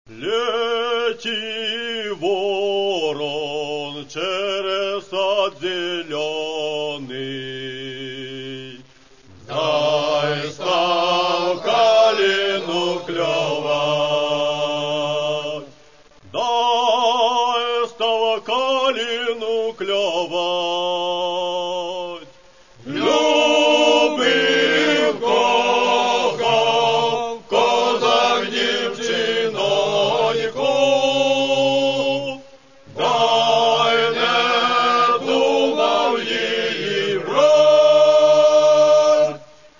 Folk
Catalogue -> Folk -> Authentic Performing